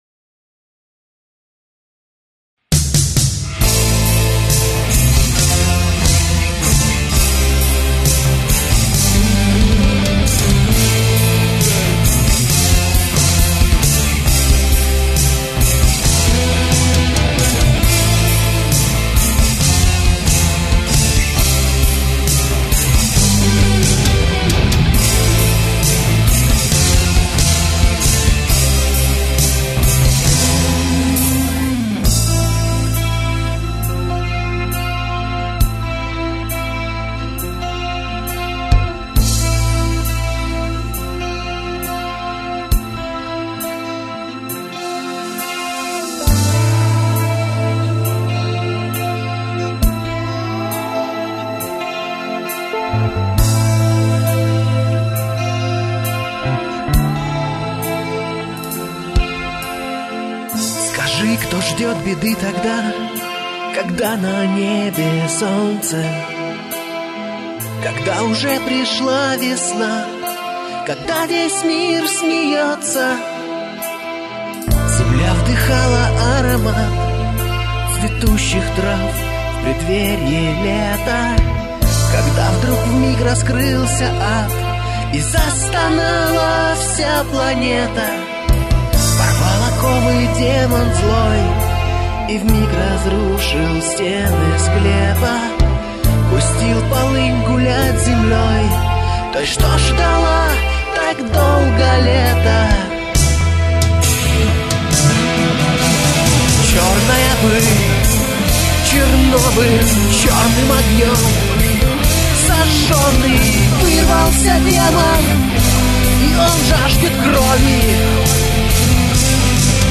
Гитары, бас, клавиши, перкуссия, вокал